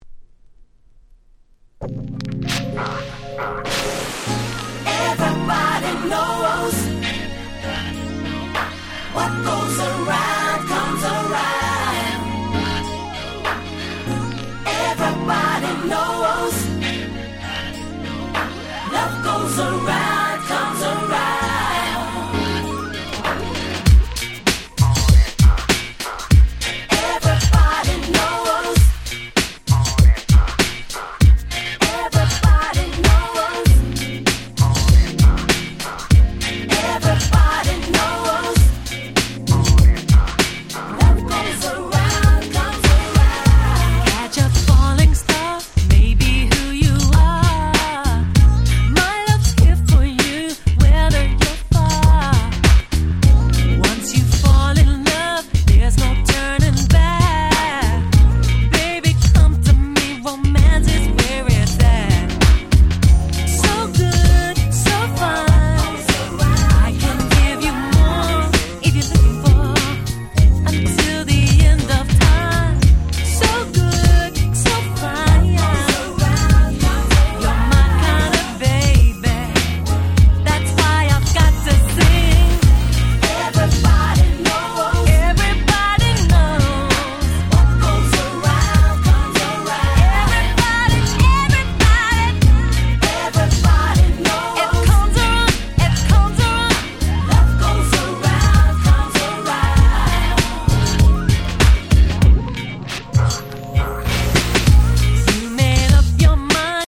97' Super Nice UK R&B !!